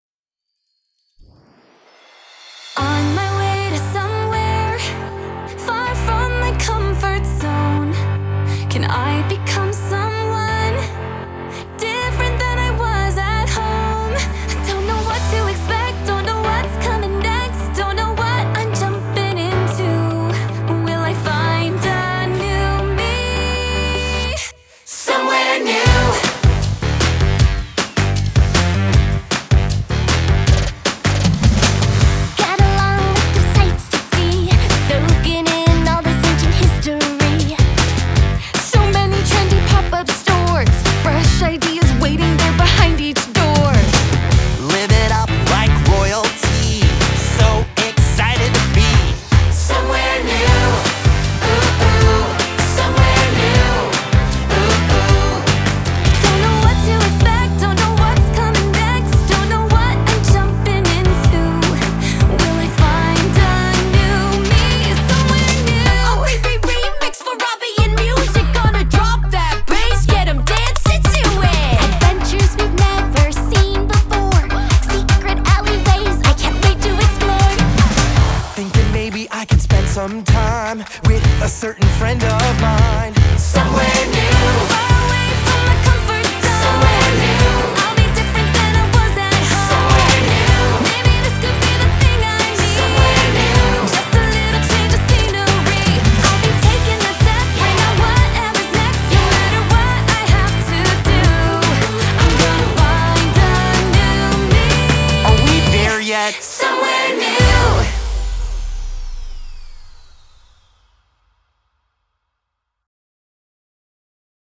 Жанр: Pop music
Genre - Pop, Aqua-core